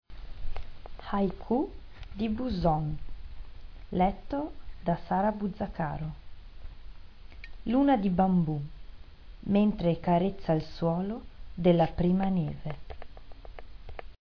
Poesie recitate da docenti
haiku_buson.mp3